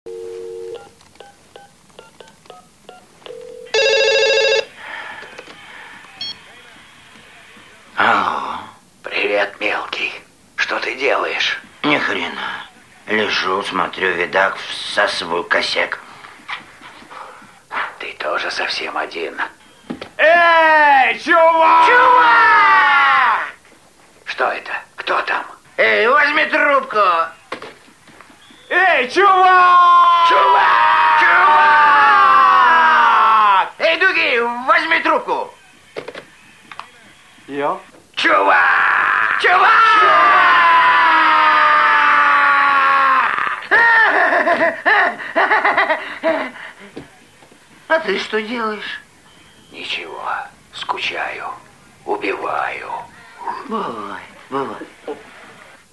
Very Scary Movie - Dude scream.
• Category: Men's scream